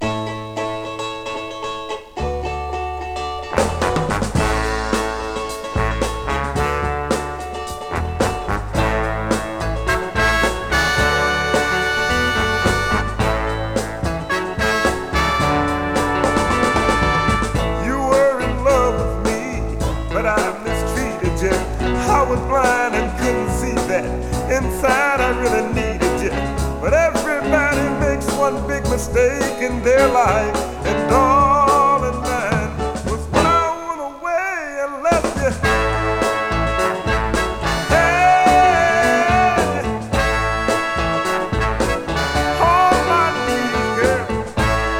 Soul　UK　12inchレコード　33rpm　Mono, Stereo